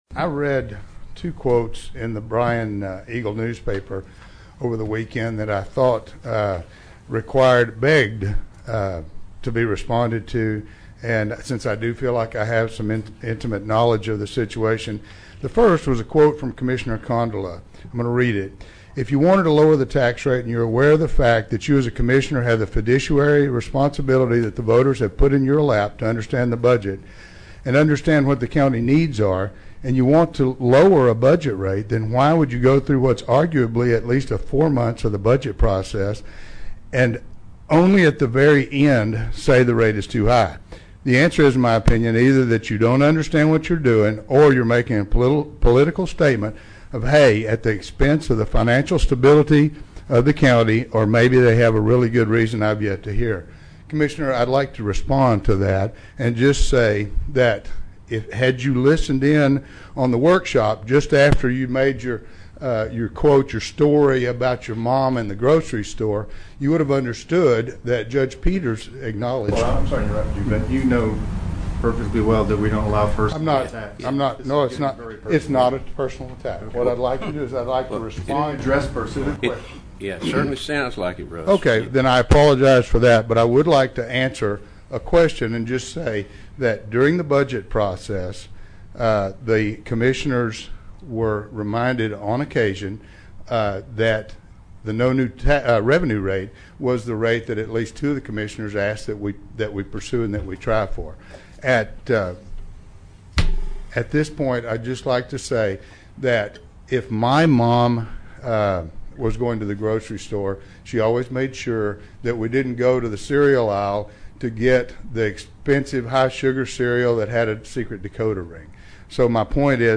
Three days after leaving office, Russ Ford was before Brazos County commissioners as a public speaker.